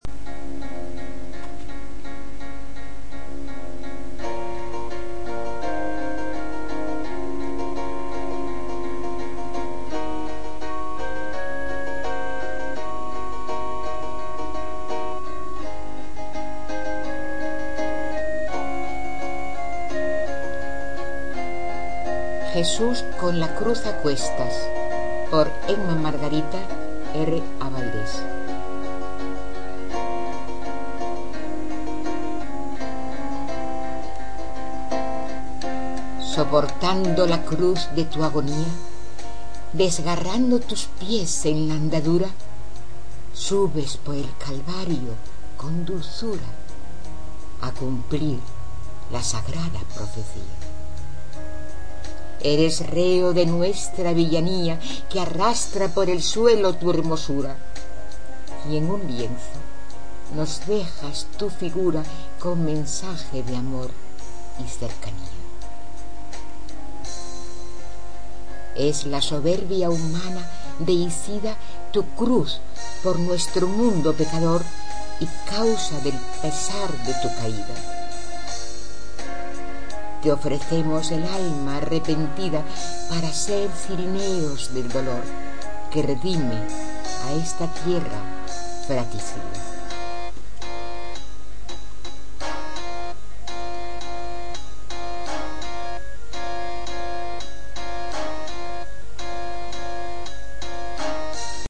Poesías